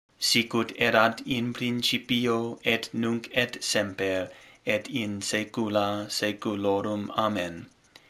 These audio recordings are designed to help new servers learn the proper pronunciation of the Latin responses.